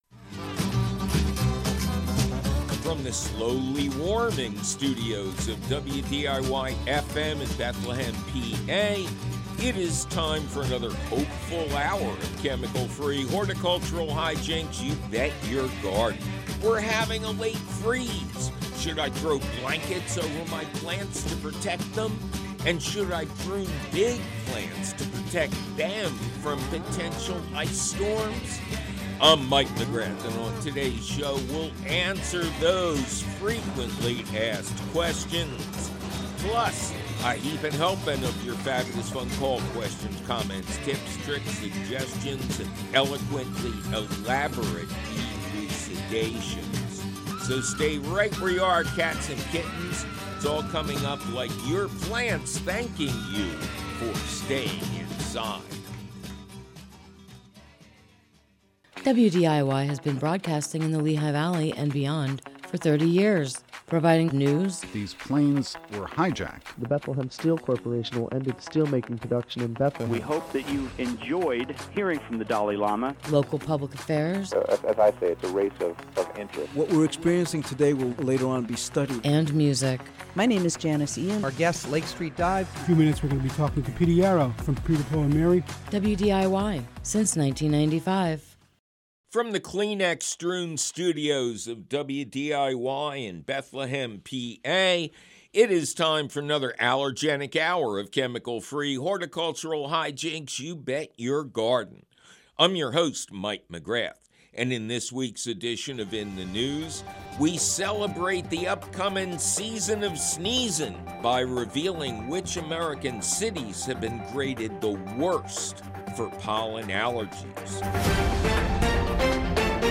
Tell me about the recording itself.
Plus, an early look at the new Square Foot Gardening book, and your fabulous phone calls!